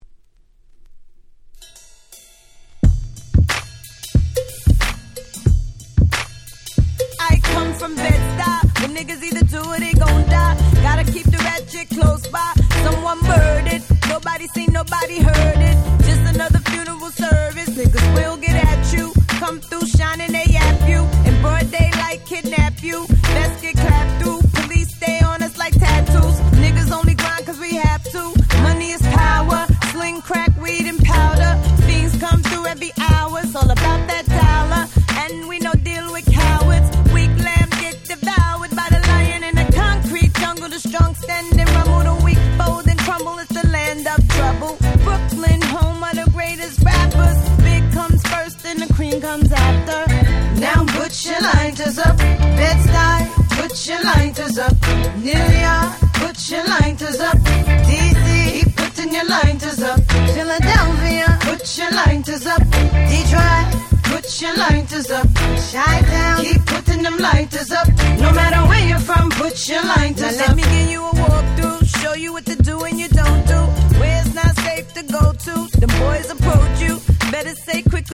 05' Big Hit Hip Hop !!
Reggae風味溢れる煙たいフロアチューン！！